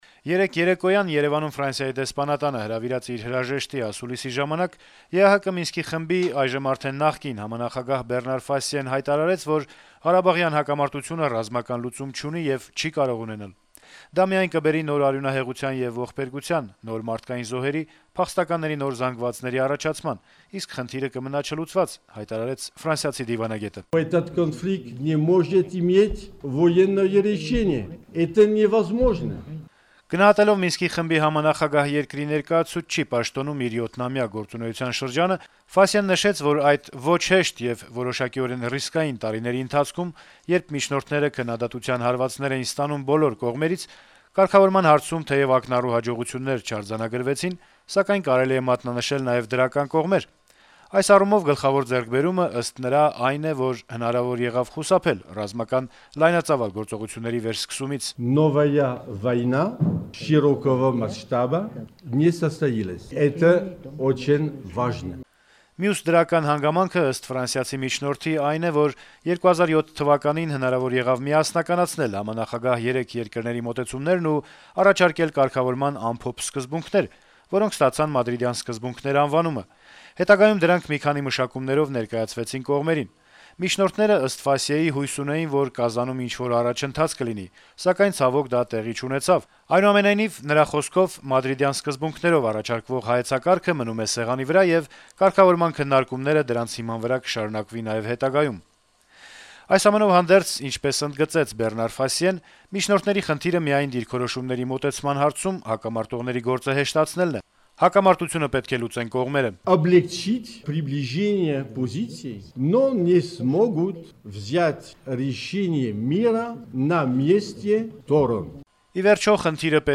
Մինսկի խմբի արդեն նախկին համանախագահ Բեռնար Ֆասիեն հրաժեշտի ասուլիս էր հրավիրել Երեւանում։